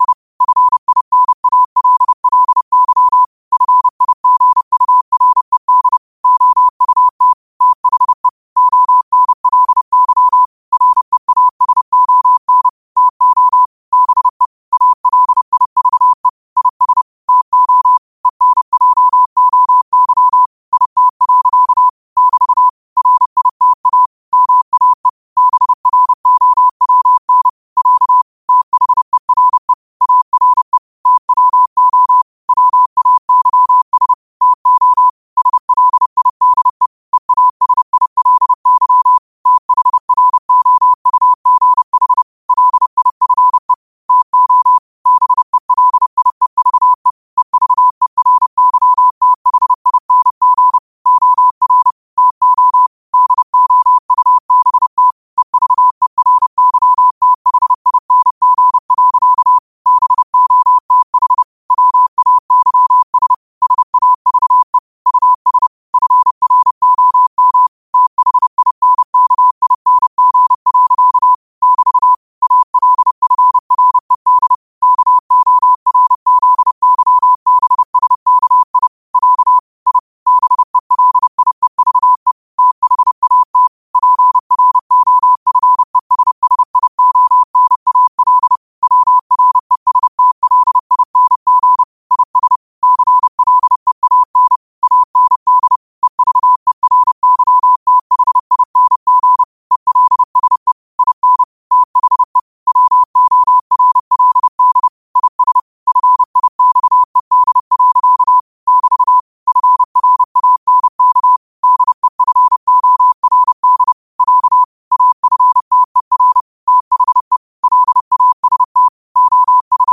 New quotes every day in morse code at 30 Words per minute.